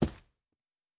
Footstep Carpet Running 1_10.wav